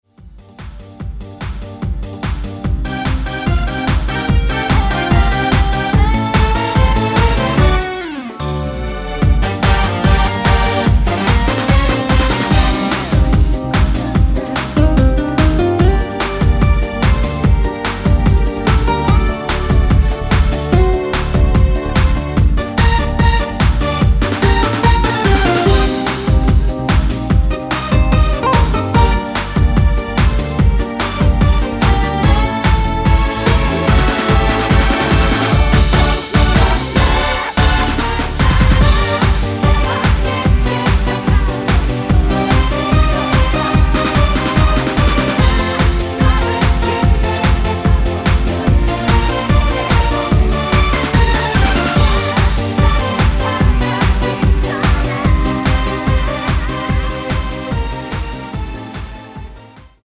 코러스 포함된 MR 입니다.(미리듣기 참조)
Gm
앞부분30초, 뒷부분30초씩 편집해서 올려 드리고 있습니다.
중간에 음이 끈어지고 다시 나오는 이유는